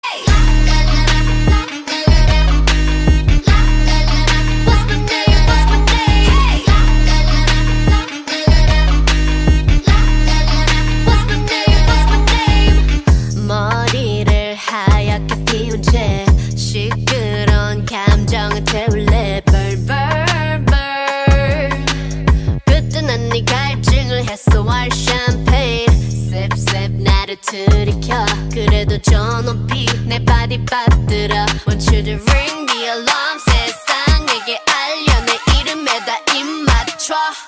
Kategori POP